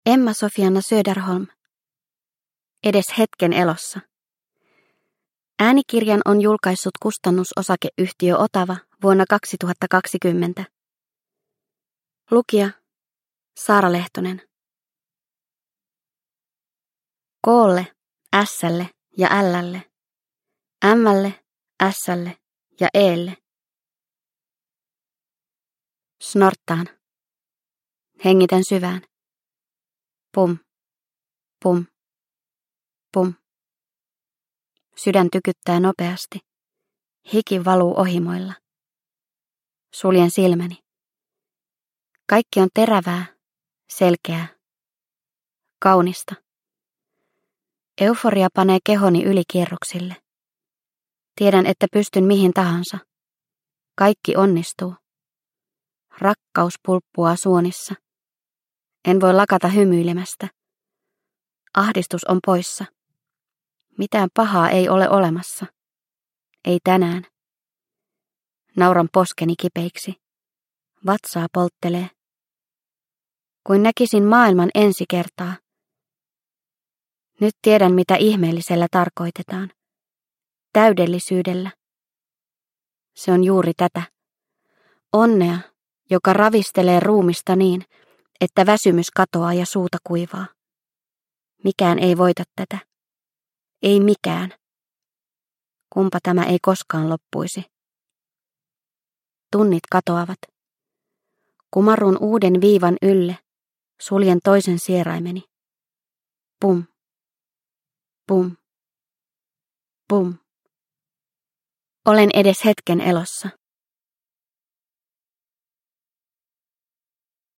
Edes hetken elossa – Ljudbok – Laddas ner